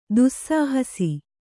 ♪ dussāhasi